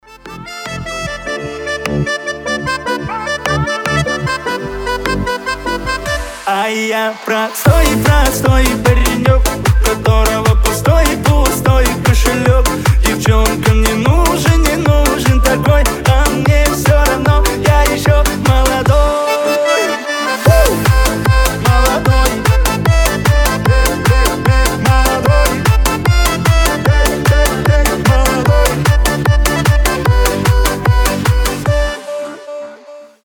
• Качество: 320, Stereo
аккордеон